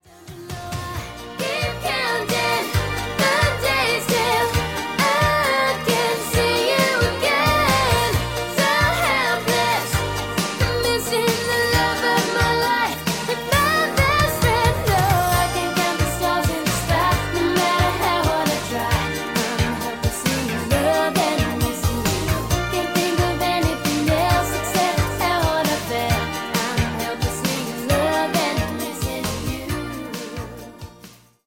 80s Pop